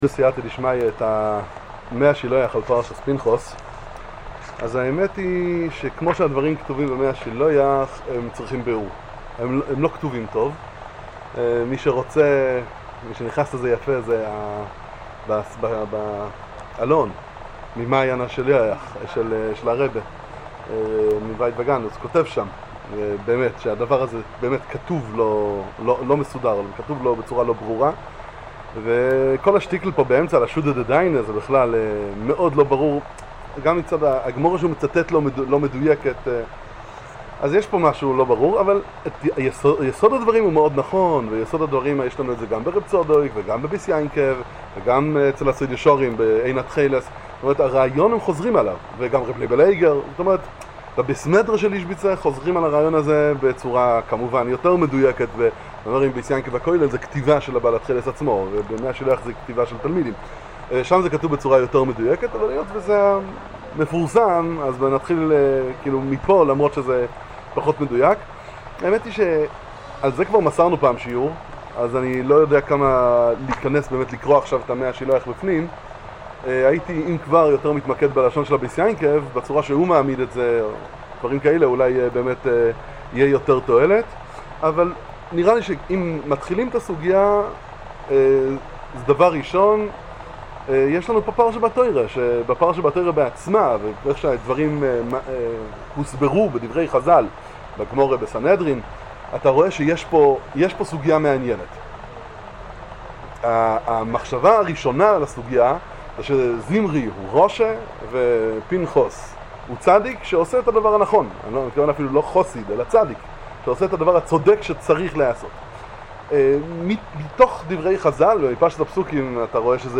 שיעור בספר מי השילוח